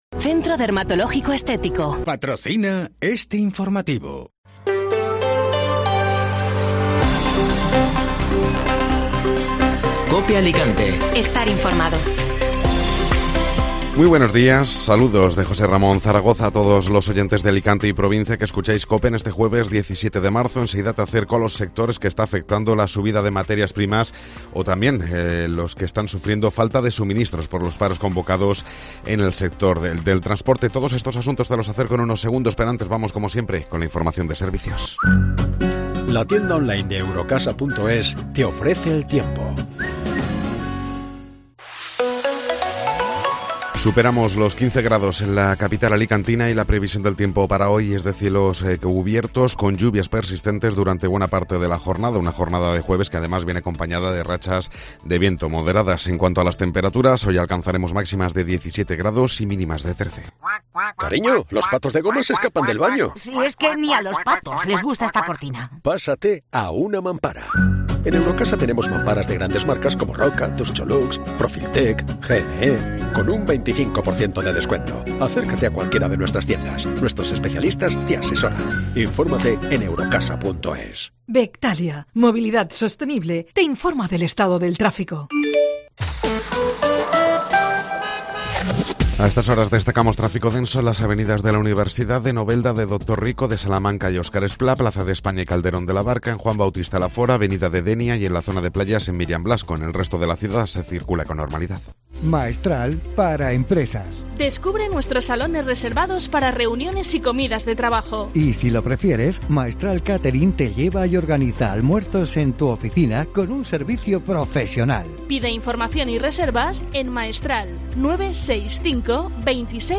Informativo Matinal (Jueves 17 de Marzo)